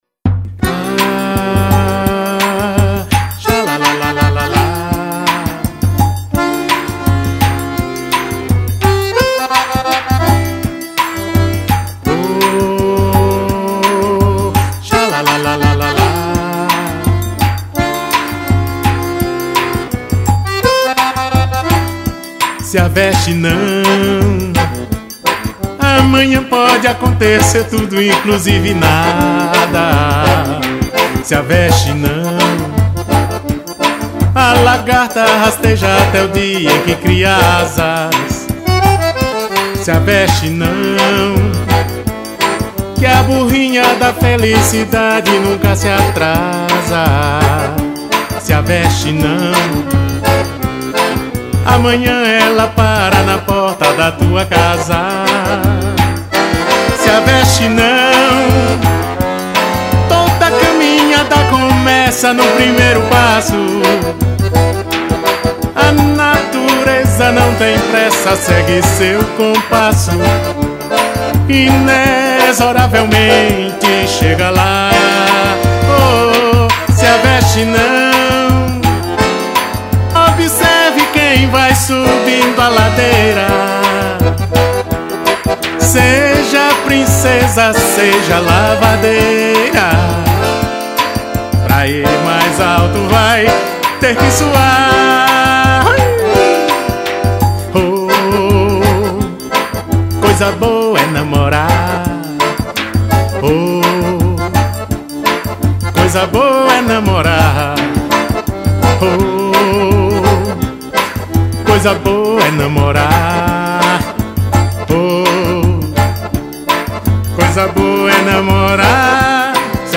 aí vai um forrozim pé-de-serra dos bons